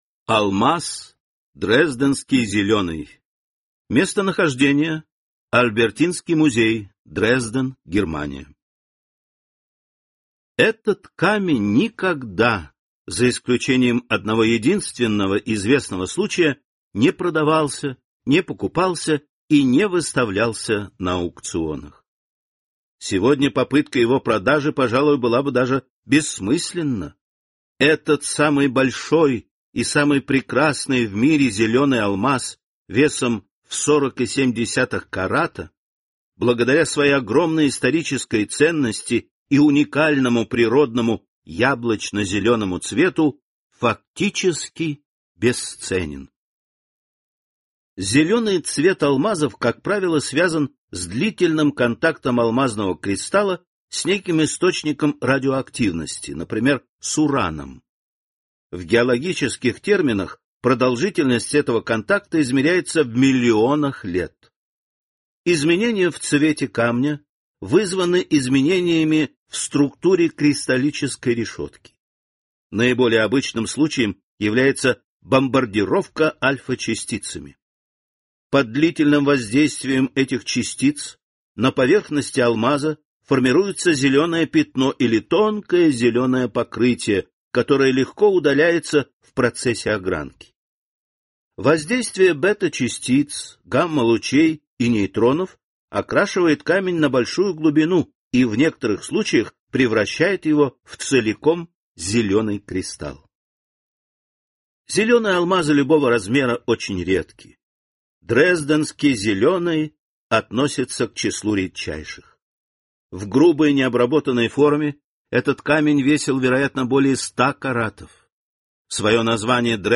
Аудиокнига Исторические реликвии | Библиотека аудиокниг